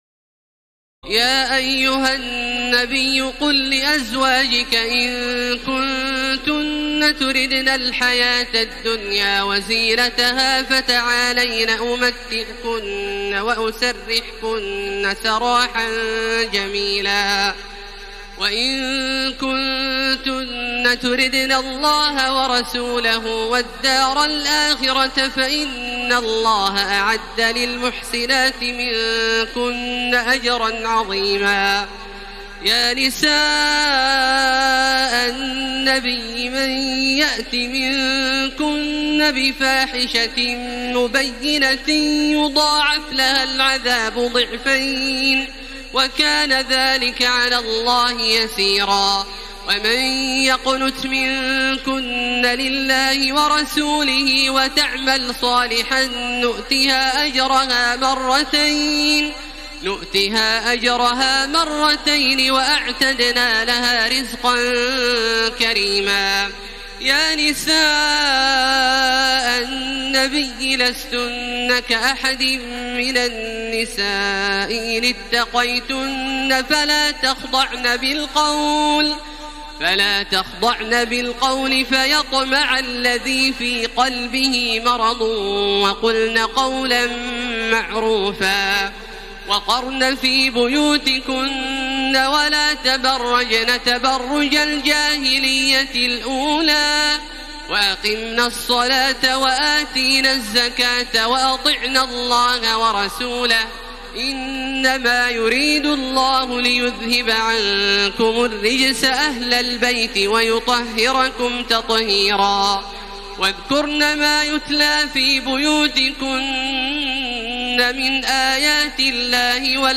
تراويح ليلة 21 رمضان 1433هـ من سور الأحزاب (28-73) وسبأ (1-23) Taraweeh 21 st night Ramadan 1433H from Surah Al-Ahzaab and Saba > تراويح الحرم المكي عام 1433 🕋 > التراويح - تلاوات الحرمين